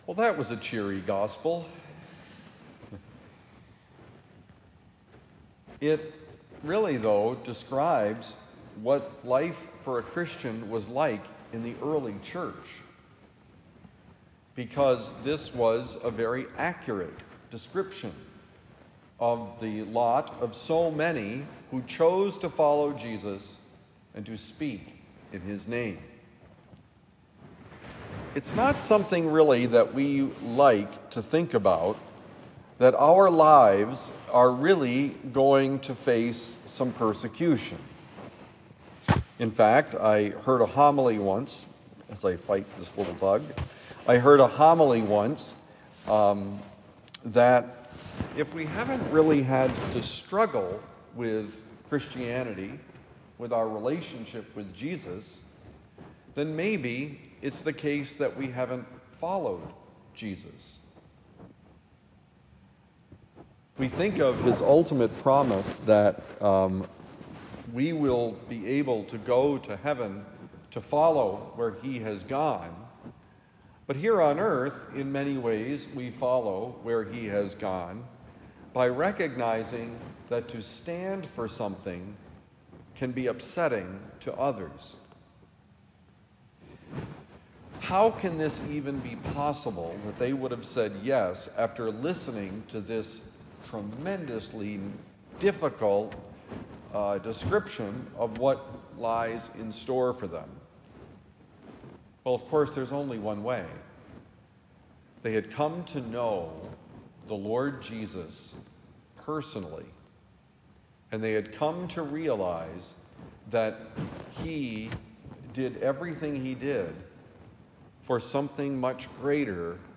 The power of hope: Homily for Friday, July 11, 2025